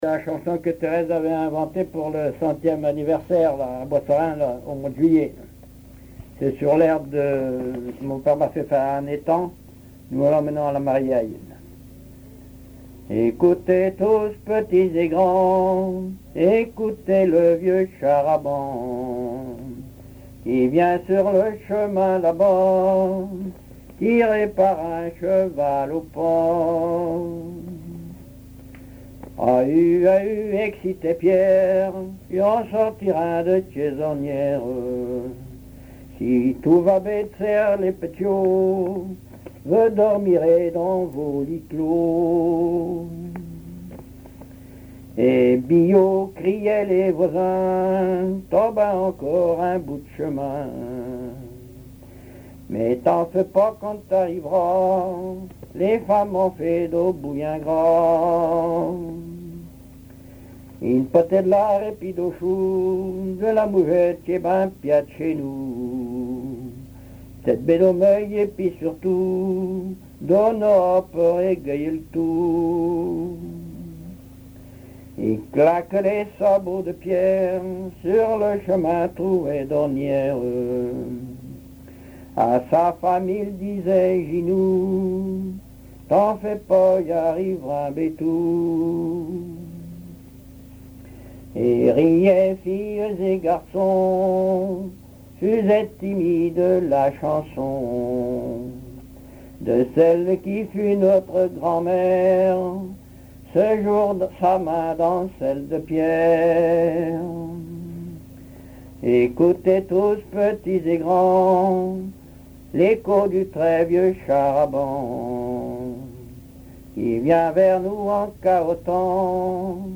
Mémoires et Patrimoines vivants - RaddO est une base de données d'archives iconographiques et sonores.
chansons populaires
Pièce musicale inédite